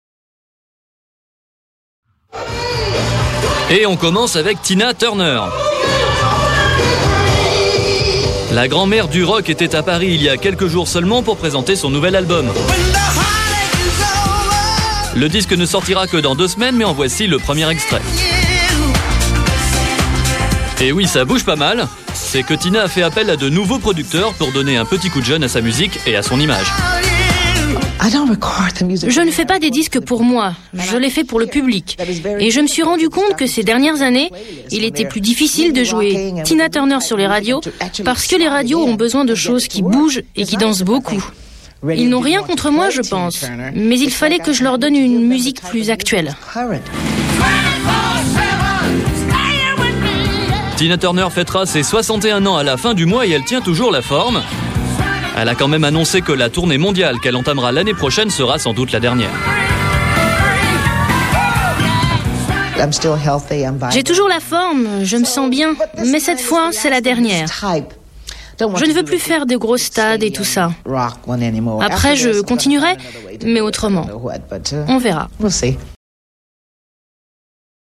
M6 Report (November 6th, 1999) on "Plus Vite Que La Musique"